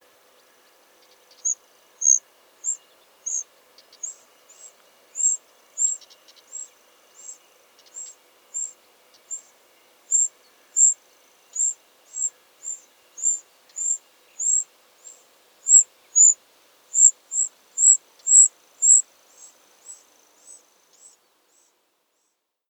koekoek
🔭 Wetenschappelijk: Cuculus canorus
koekoek_bedelend.mp3